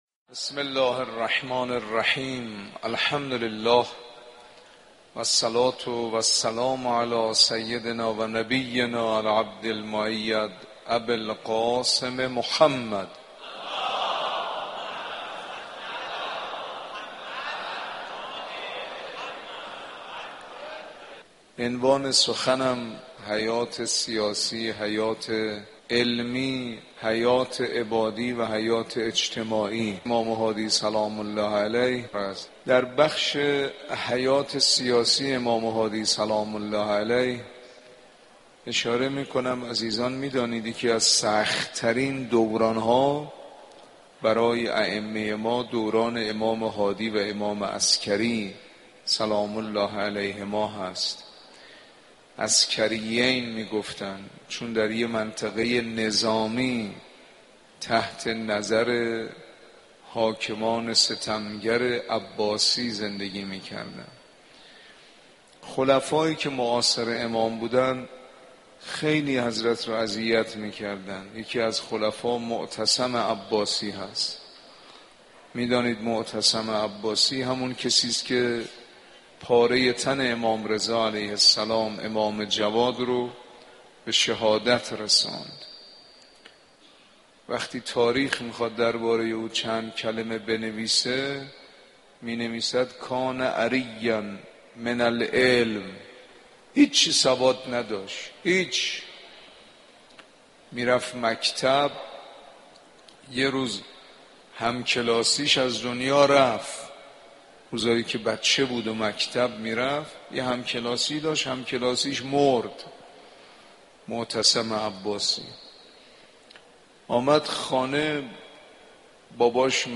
صوت سخنرانی مذهبی و اخلاقی یکی از سخت ترین دوران ها برای اهل بیت (ع) دوران امامت امام هادی و امام حسن عسگری علیهم السلام است.